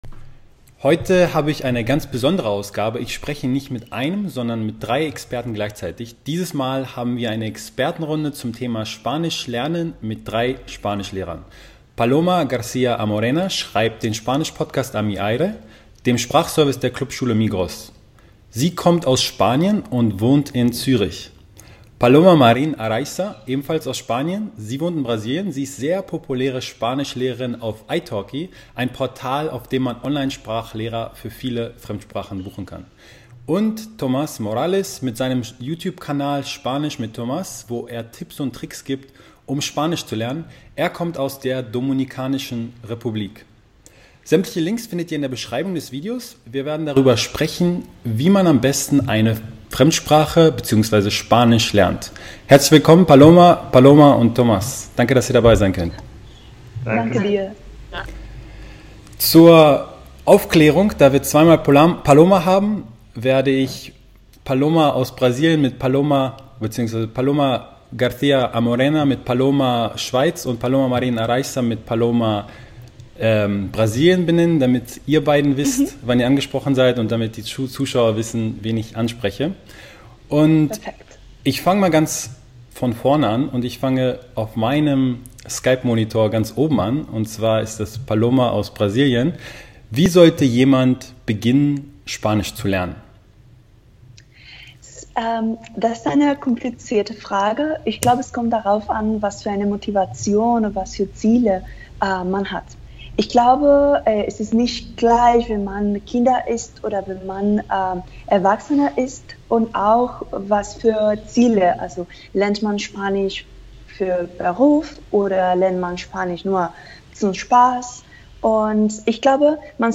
Spanisch lernen – 3 muttersprachliche Spanischlehrer geben Dir hilfreiche Tipps und Ratschläge (aus ihrer Praxis)!